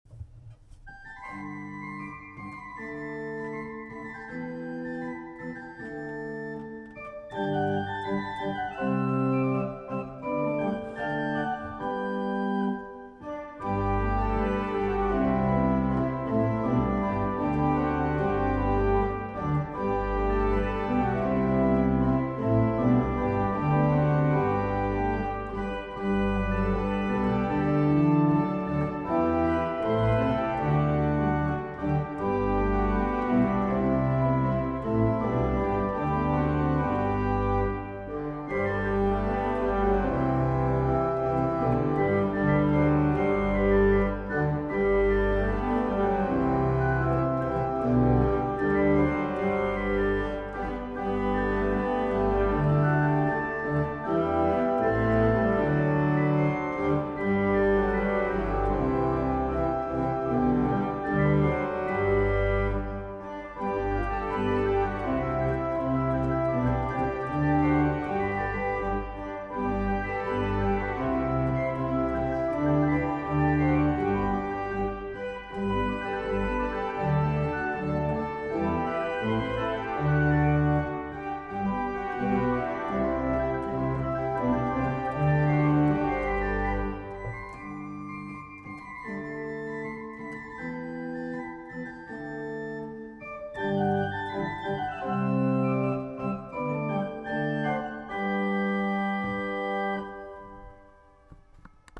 auf der Orgel in der Melanchthonkirche